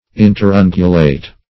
Meaning of interungulate. interungulate synonyms, pronunciation, spelling and more from Free Dictionary.
Search Result for " interungulate" : The Collaborative International Dictionary of English v.0.48: Interungular \In`ter*un"gu*lar\, Interungulate \In`ter*un"gu*late\, a. (Anat.)